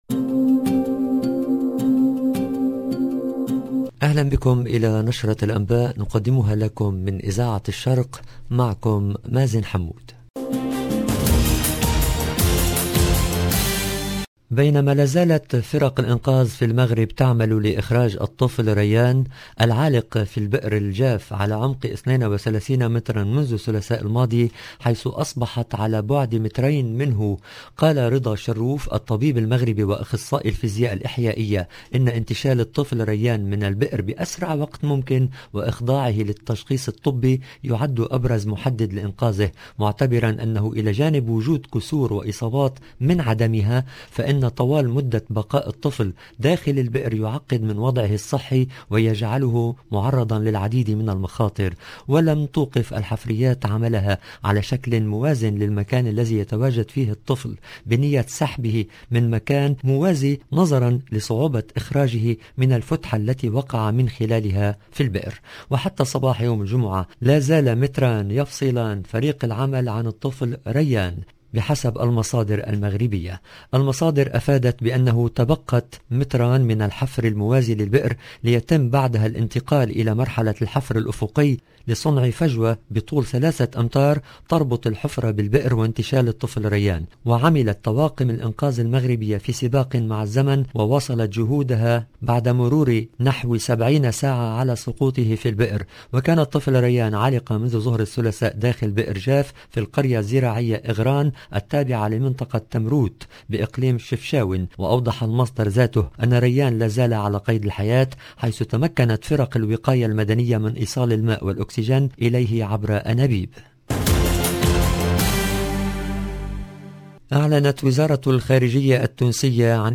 LE JOURNAL DU SOIR EN LANGUE ARABE DU 4/02/22